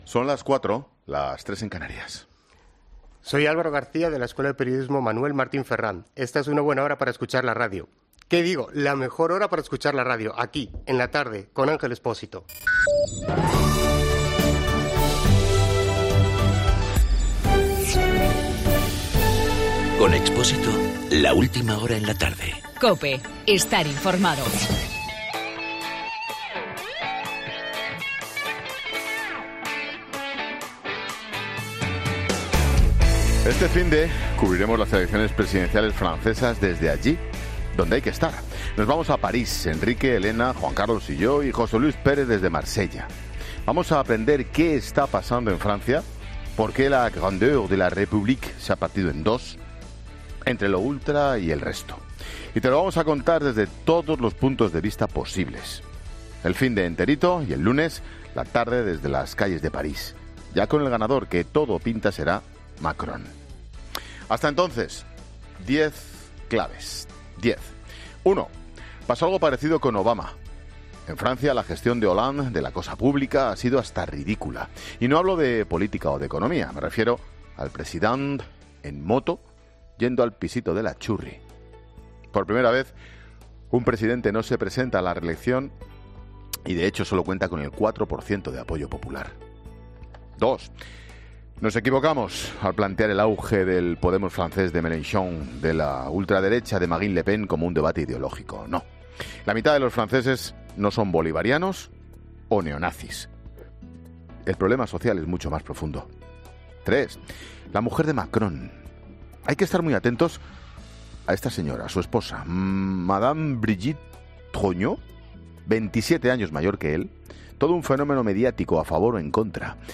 AUDIO: Monólogo 16h.